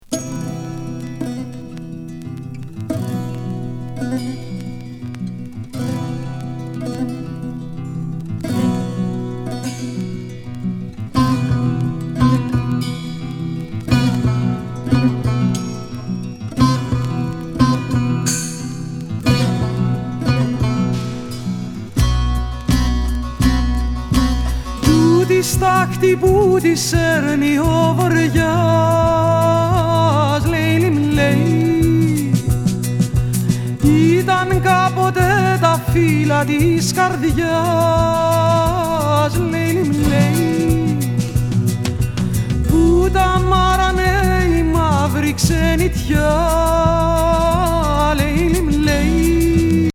スピリチュアル